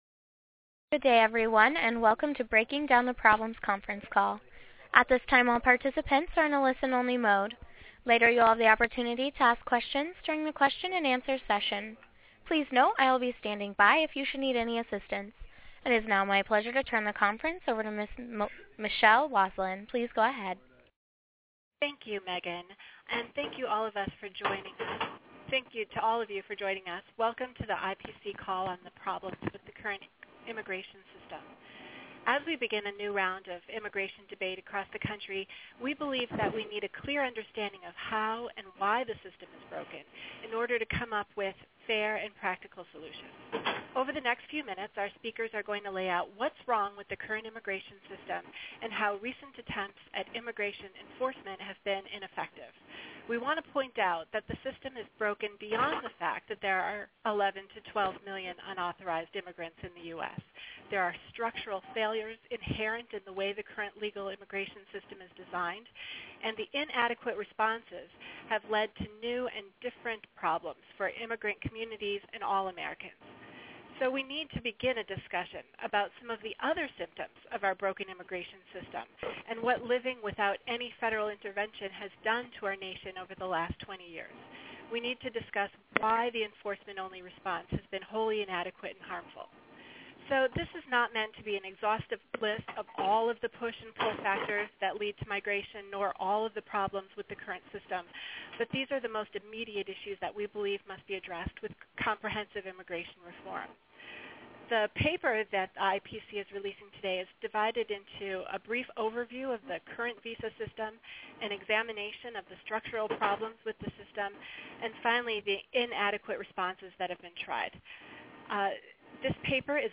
Listen to the telephonic briefing in .MP3